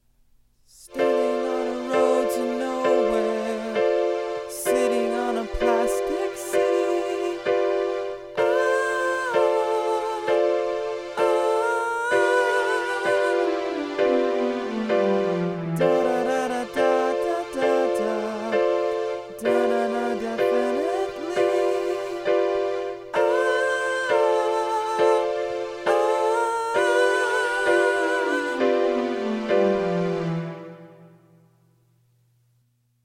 quick demo.